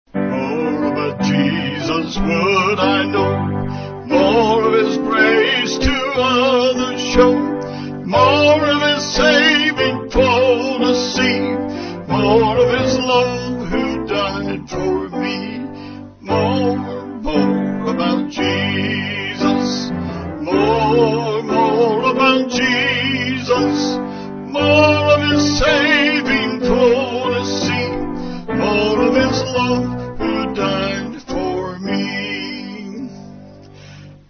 8.8.8.8 with Refrain
Band